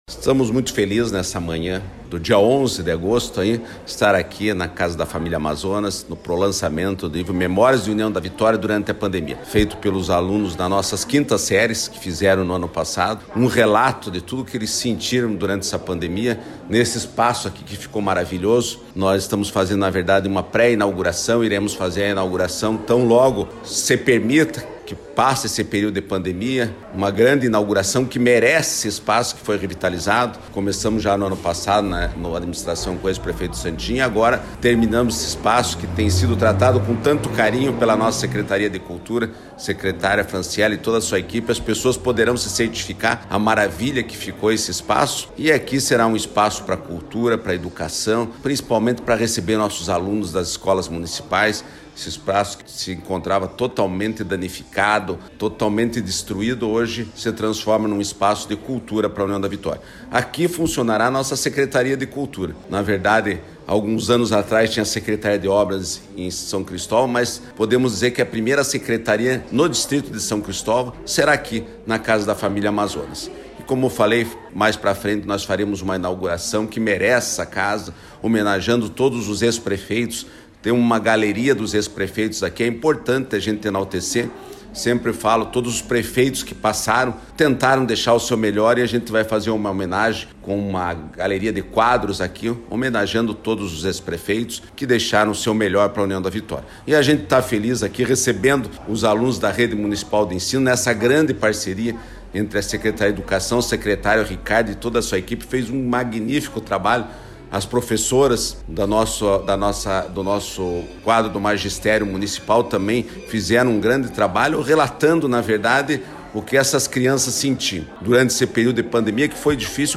“Esse espaço nos enche de muita alegria”, disse o prefeito Bachir Abbas.